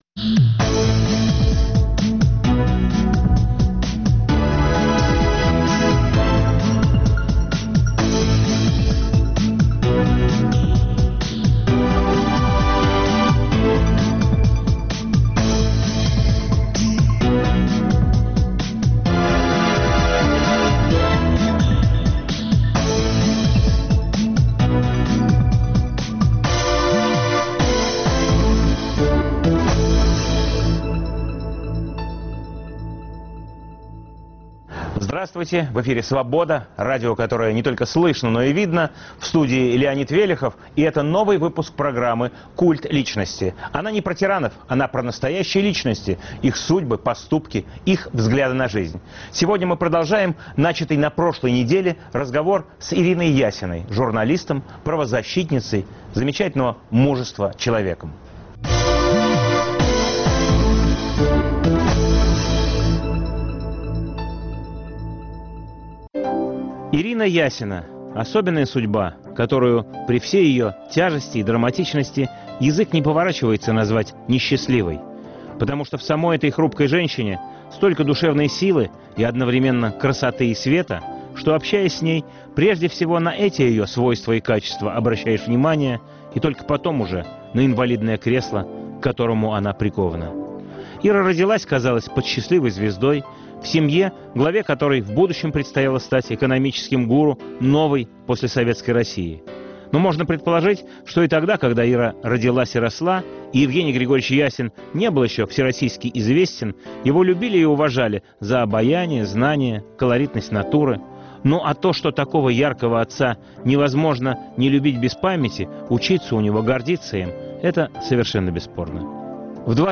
Новый выпуск программы о настоящих личностях, их судьбах, поступках и взглядах на жизнь. В студии общественный деятель, правозащитница Ирина Ясина. Эфир в субботу 21 мая в 18 часов 05 минут Ведущий - Леонид Велехов.